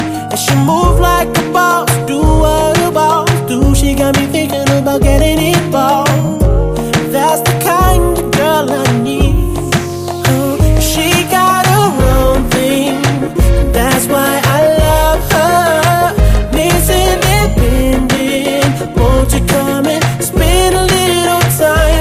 This is a sound sample from a commercial recording.
Reduced quality: Yes